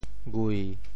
Details of the phonetic ‘ghui7’ in region TeoThew